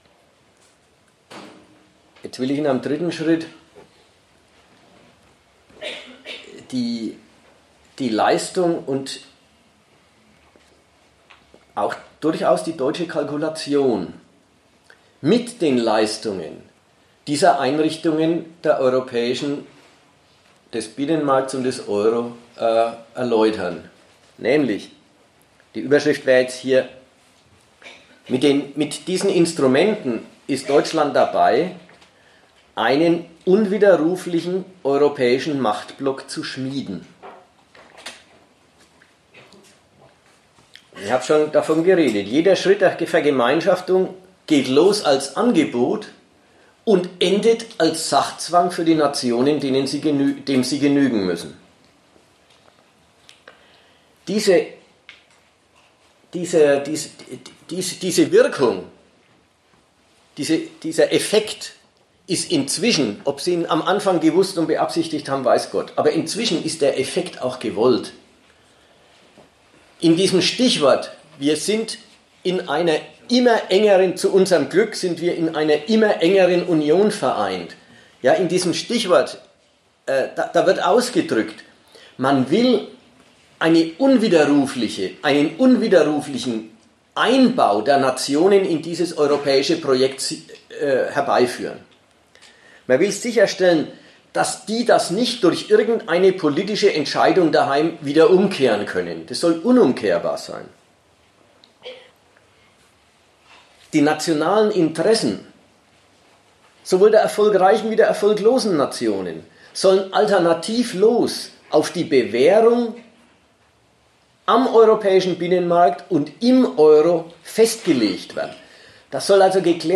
Ort Nürnberg
Dozent Gastreferenten der Zeitschrift GegenStandpunkt